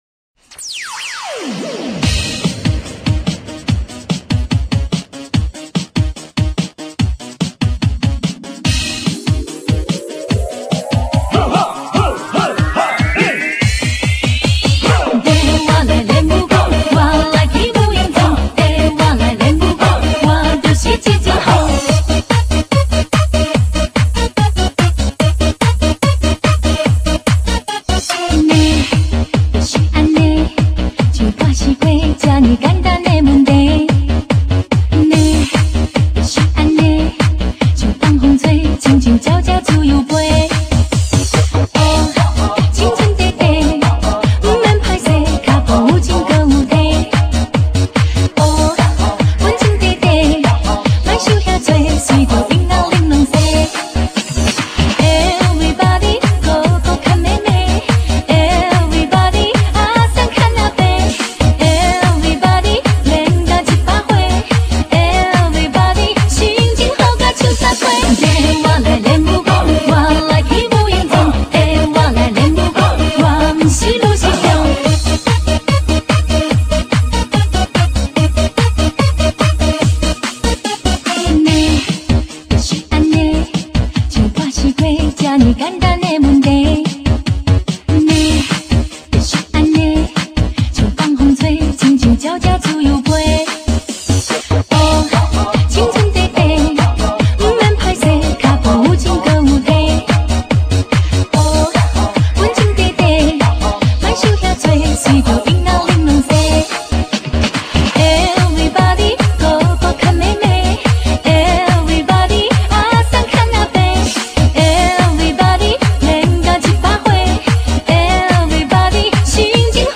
華語台語